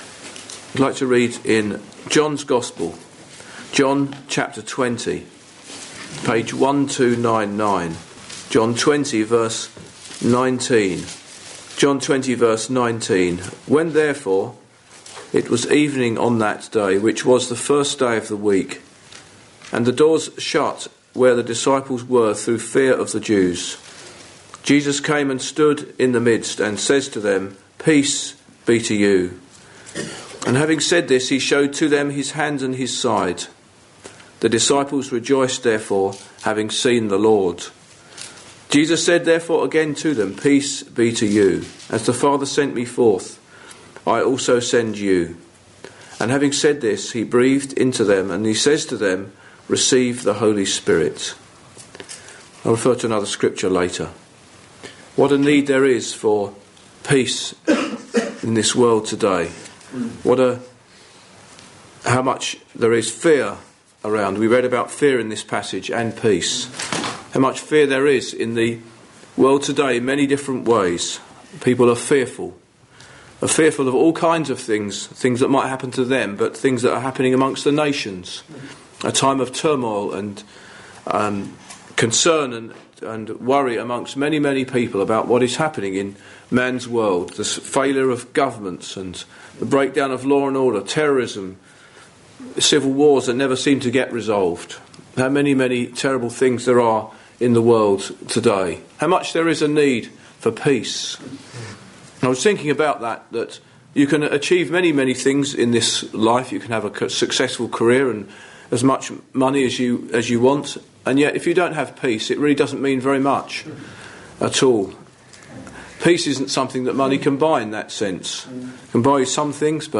Peace-Be-to-You-Gospel-Worthing.mp3